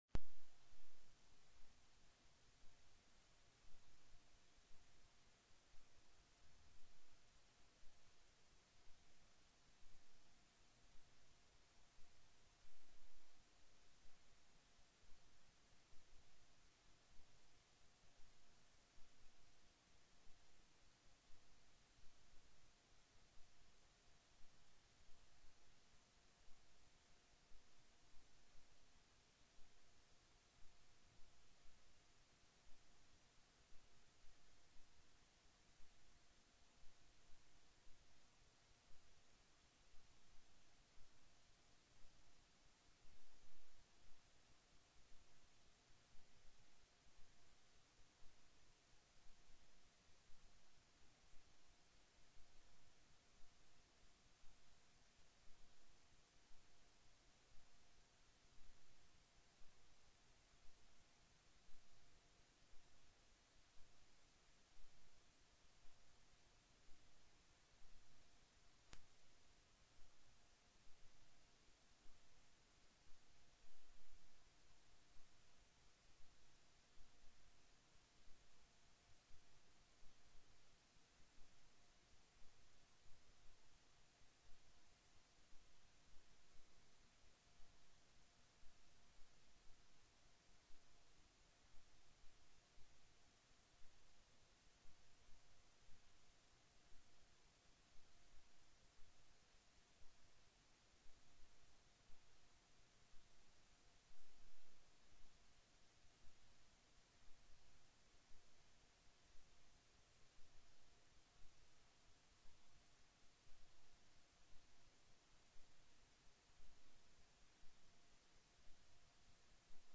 02/11/2009 09:00 AM Senate FINANCE
Overview: Retirement Trust Performance TELECONFERENCED